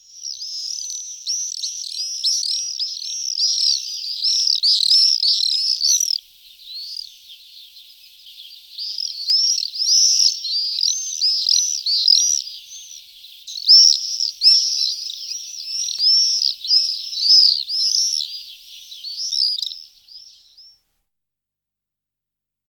Погрузитесь в мир звуков стрижей – их звонкое щебетание напомнит о теплых летних днях.
Звуки черных стрижей